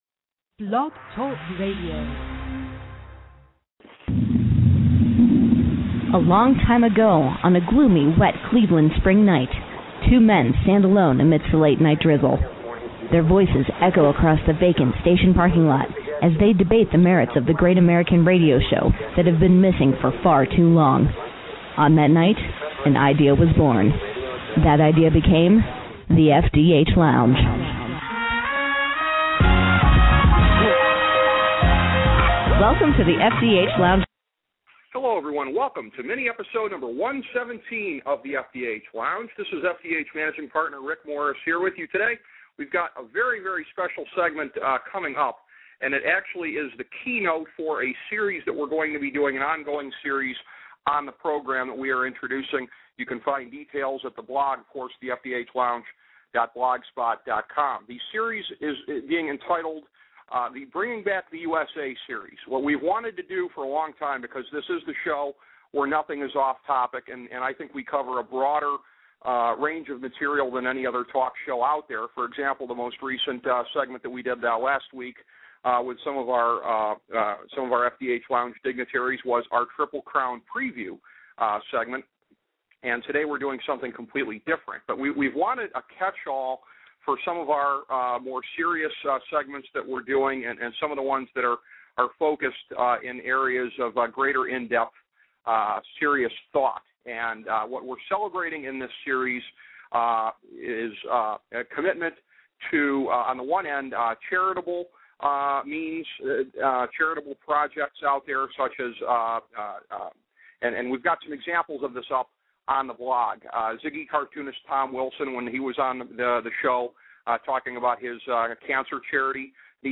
A conversation with political activist Joe DioGuardi